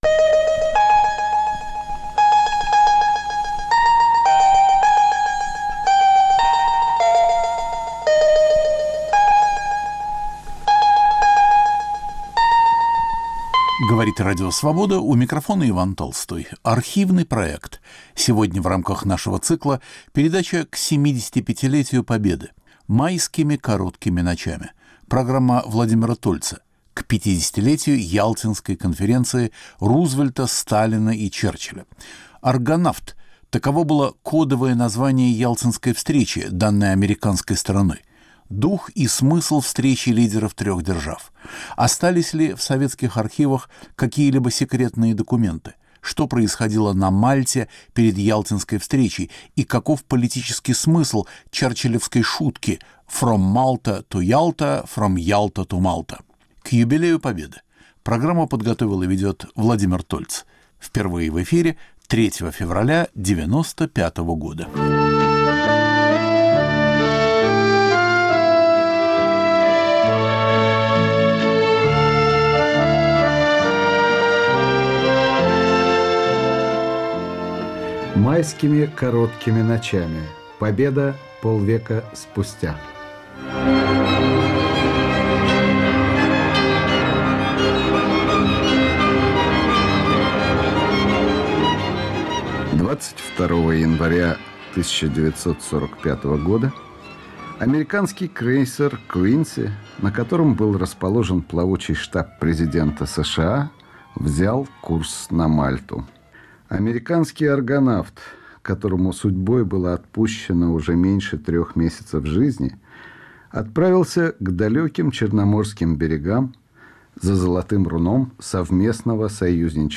Архивный проект. Иван Толстой выбирает из нашего эфира по-прежнему актуальное и оказавшееся вечным.